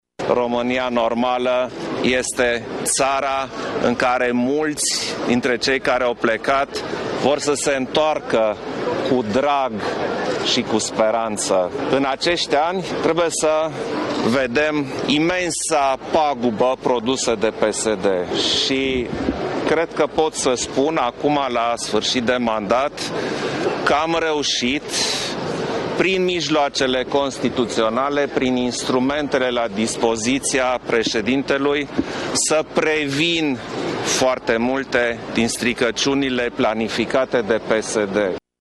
Președintele Klaus Iohannis și-a depus la Biroul Electoral Central din București, în jurul orei 12,00, candidatura pentru un nou mandat, din partea PNL.
Șeful statului a spus, cu această ocazie, că avem nevoie de o Românie normală și de un guvern pro-european și că trebuie să se reparea ceea ce PSD-ul a stricat: